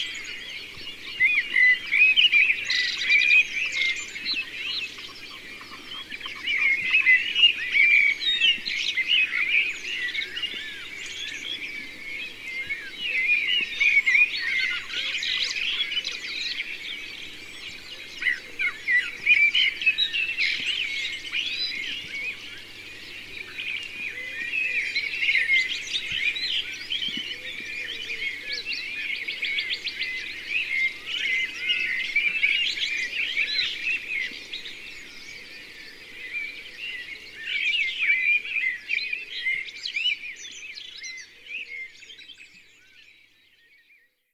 • birds singing in mixed forest.wav
birds_singing_in_mixed_forest_F4r.wav